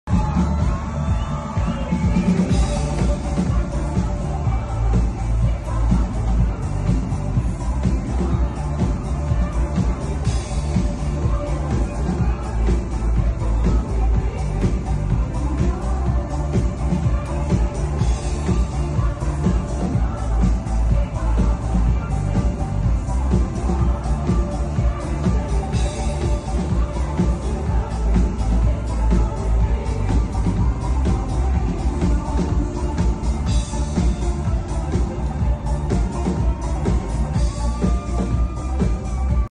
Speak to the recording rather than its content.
ATLANTA